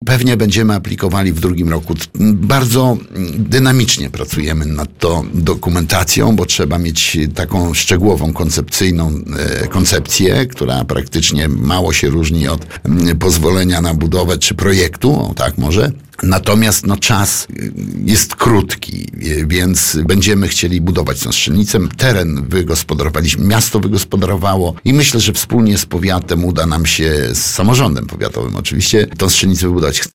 Jak mówił na naszej antenie burmistrz Kolna Andrzej Duda potrzeba czasu, aby dobrze przygotować aplikację do naboru.